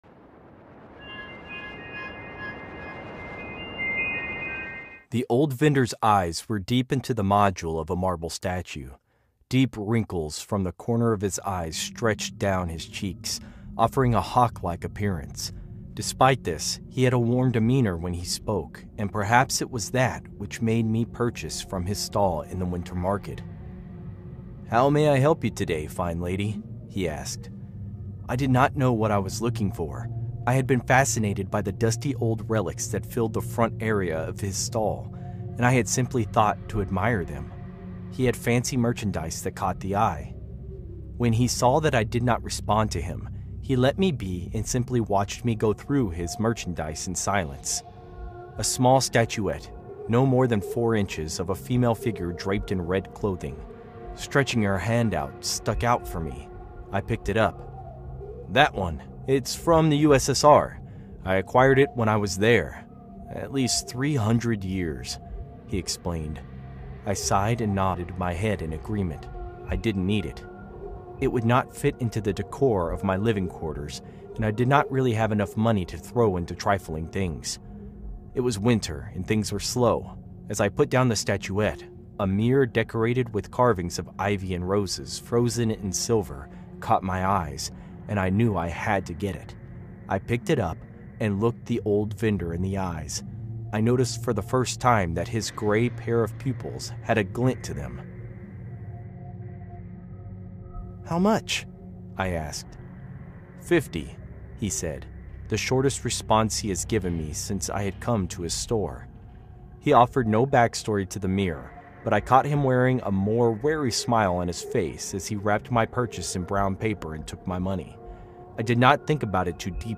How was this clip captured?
Before the story begins, Scary Stories wants you to know something important: all advertisements are placed at the very beginning of each episode so nothing interrupts the experience once the darkness settles in.